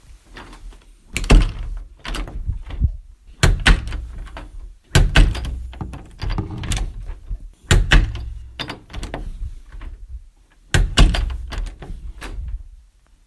门（洗衣机）
描述：关上我的旧洗衣机的门。